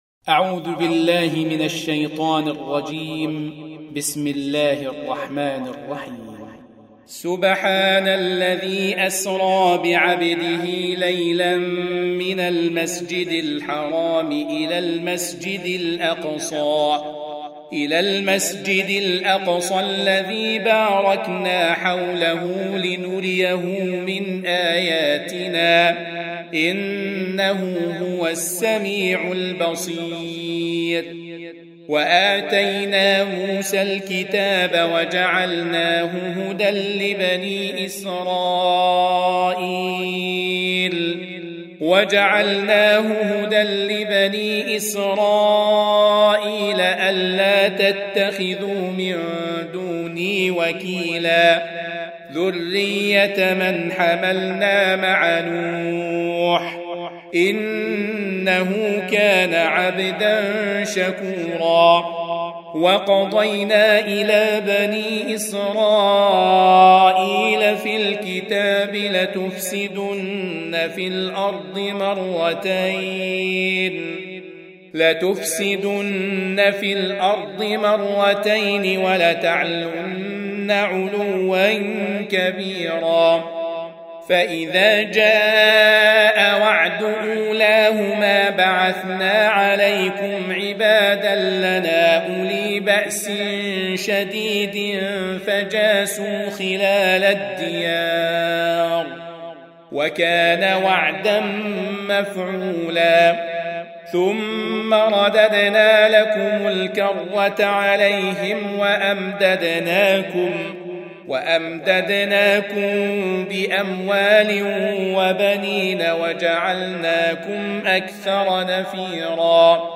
17. Surah Al-Isr�' سورة الإسراء Audio Quran Tarteel Recitation
Surah Sequence تتابع السورة Download Surah حمّل السورة Reciting Murattalah Audio for 17. Surah Al-Isr�' سورة الإسراء N.B *Surah Includes Al-Basmalah Reciters Sequents تتابع التلاوات Reciters Repeats تكرار التلاوات